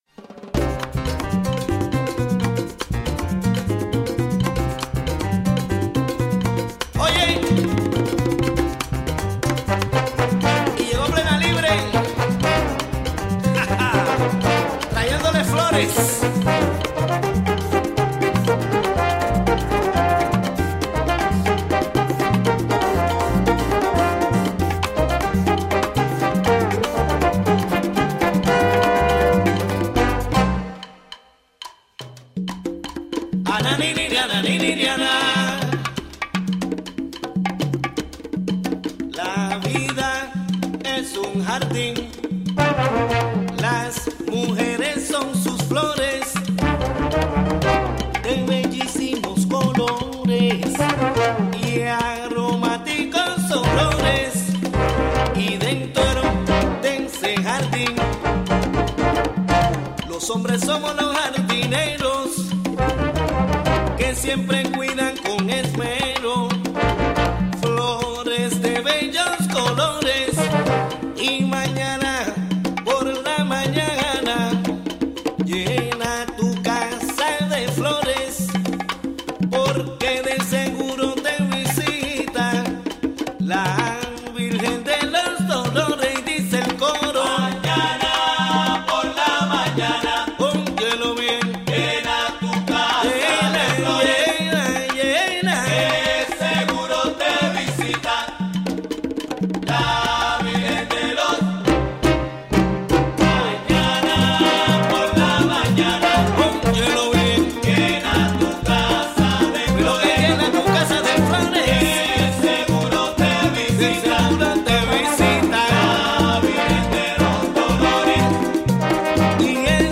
A discussion of upcoming immigration clinics and DACA services, the Catskill Strolls, Sat., Dec. 14 and the Bard College Conservatory Orchestra concert scheduled for the same day.